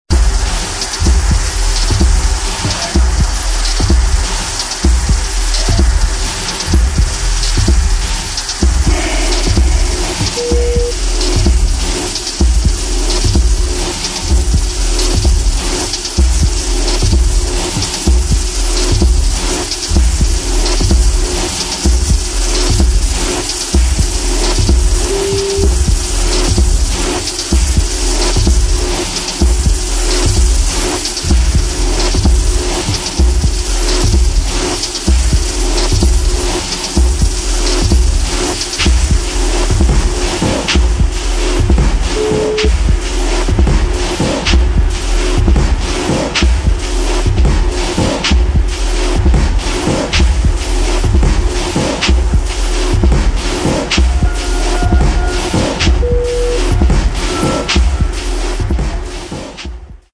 TECHNO / EXPERIMENTAL